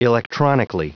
Prononciation du mot electronically en anglais (fichier audio)
Prononciation du mot : electronically